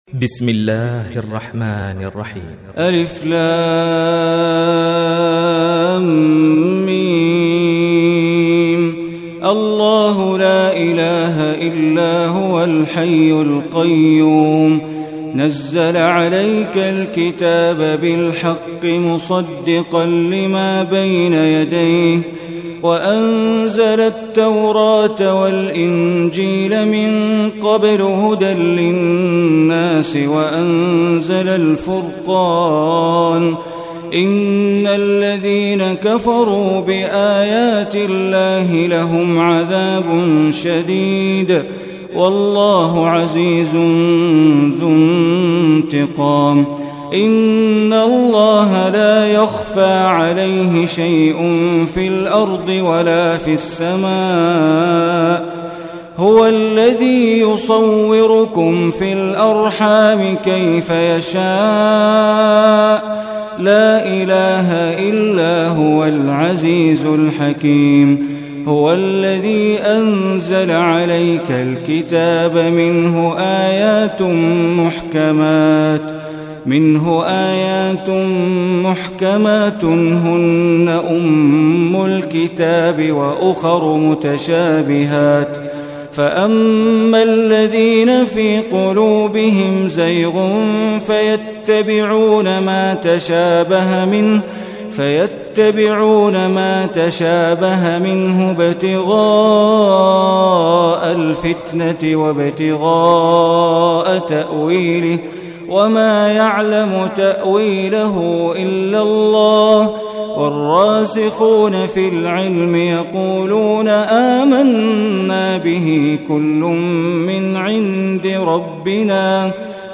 Surah Imran Recitation by Sheikh Bandar Baleela
Surah Imran, is third surah of Holy Quran. Listen or play online recitation of Surah Imran in the voice of Sheikh Bandar Baleela.